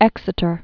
(ĕksĭ-tər)